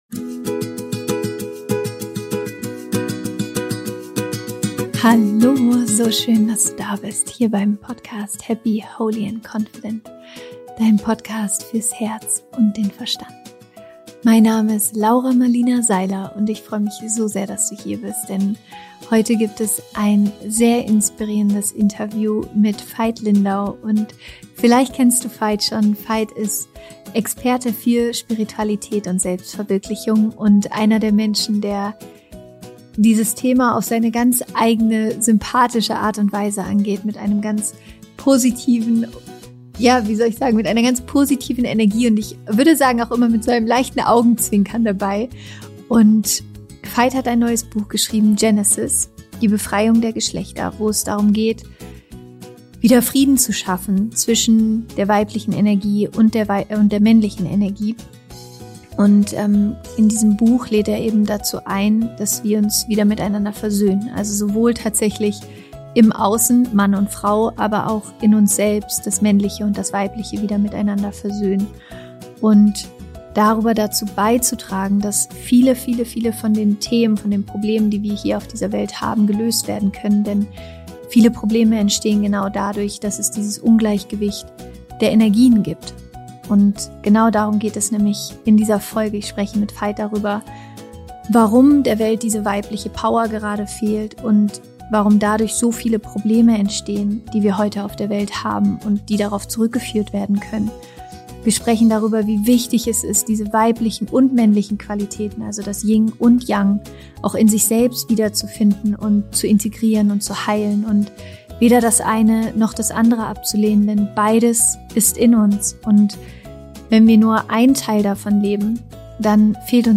Wir sprechen darüber, wie wir heute eigentlich die Geschlechterrollen leben und wie sehr die männlichen und weiblichen Qualitäten in uns häufig im Ungleichgewicht sind.